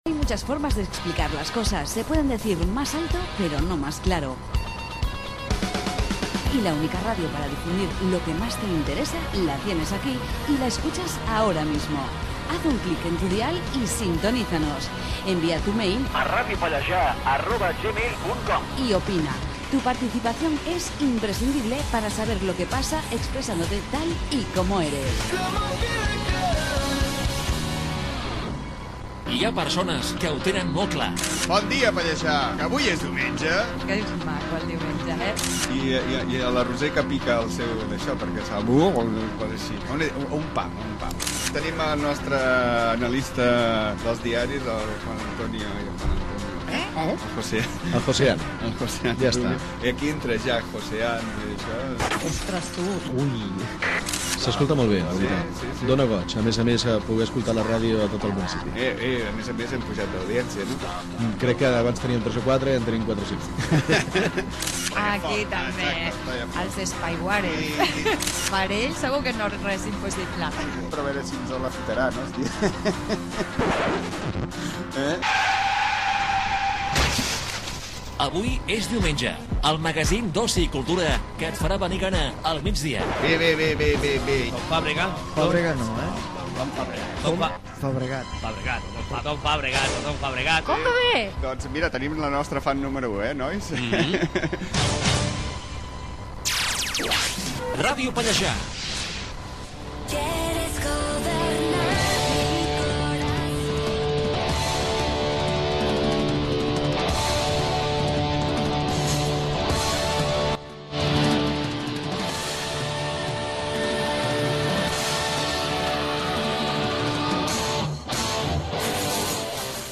careta del programa, presentació de l'equip
Gènere radiofònic Entreteniment